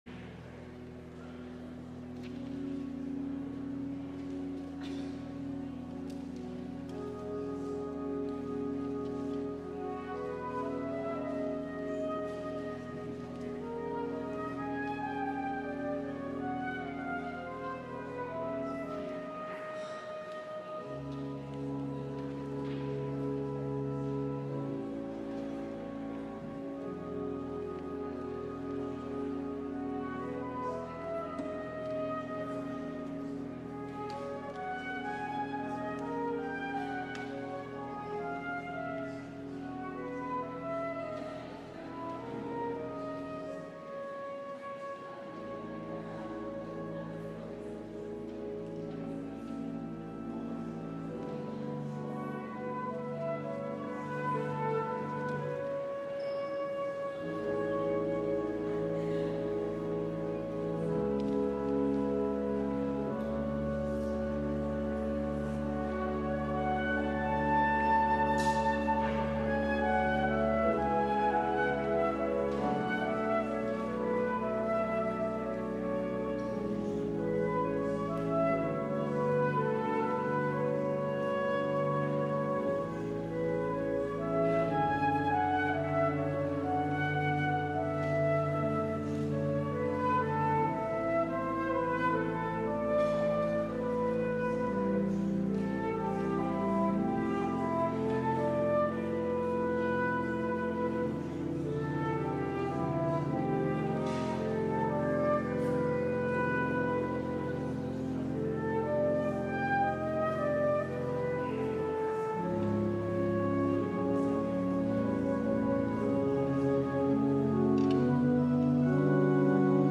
LIVE Evening Worship Service